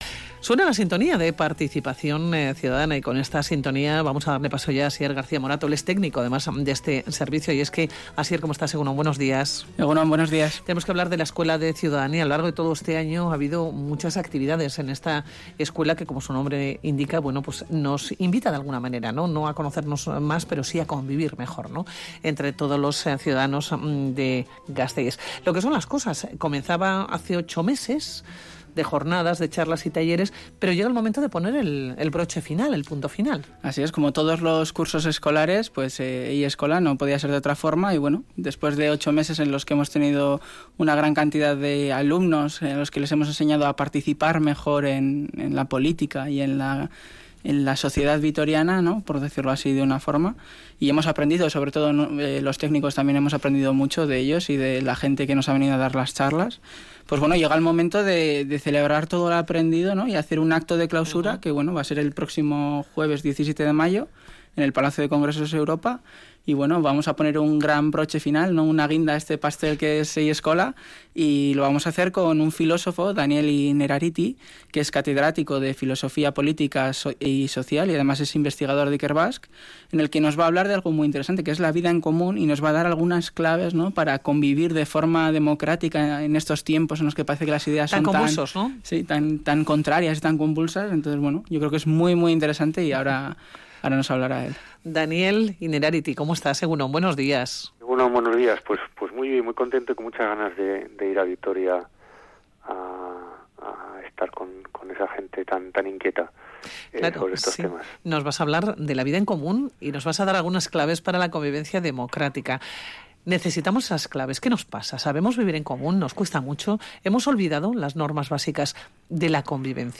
Audio: Entrevista con Daniel Innerarity en Radio Vitoria el 14 de mayo de 2018